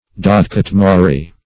Search Result for " dodecatemory" : The Collaborative International Dictionary of English v.0.48: Dodecatemory \Do*dec`a*tem"o*ry\, n. [Gr.
dodecatemory.mp3